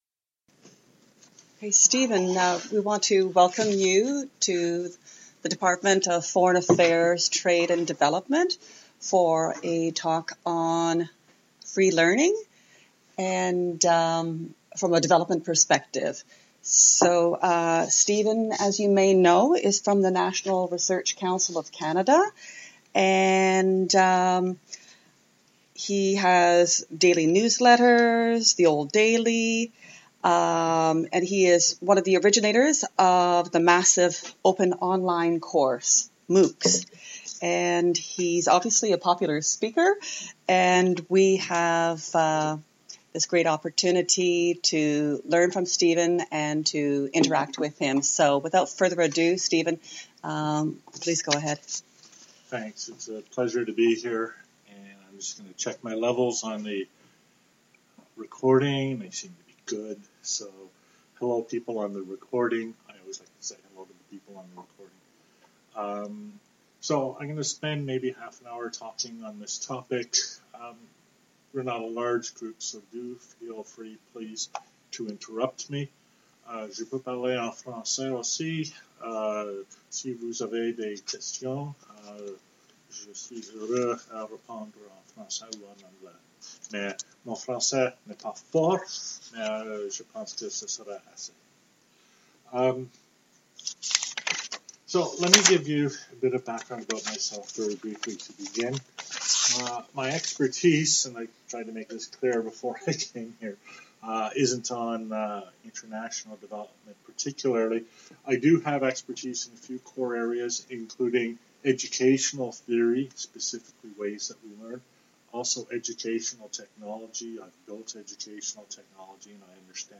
The slides in this presentation address: first, the relation between connectivism and free learning; second, the development of our understanding of networks and network technology; and third, the policy framework needed to enourage and promote free learning for development. The audio doesn't finish the slides but is an engaging discussion between myself and DFATDC staff.